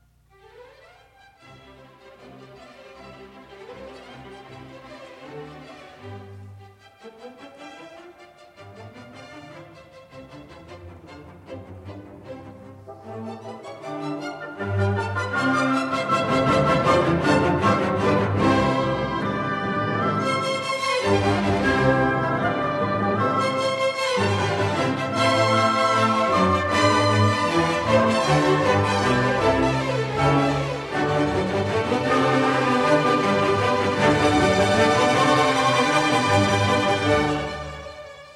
Now, comes the first theme, very playful and in allegro molto e vivace.